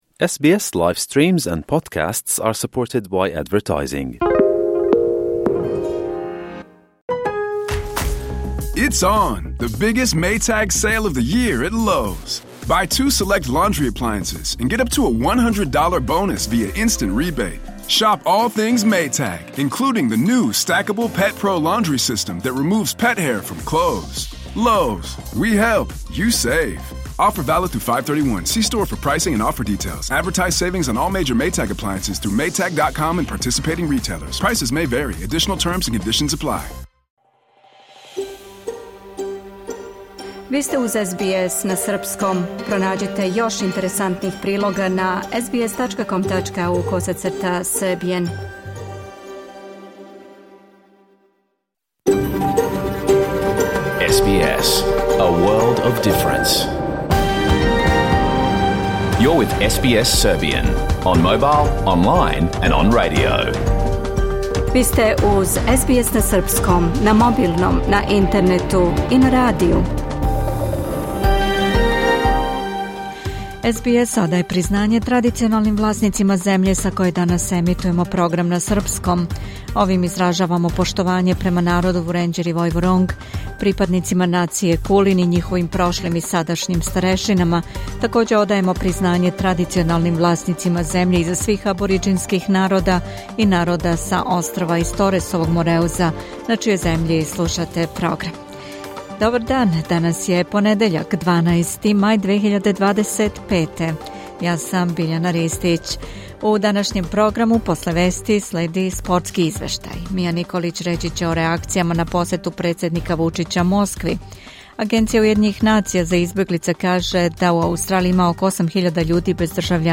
Програм емитован уживо 12. маја 2025. године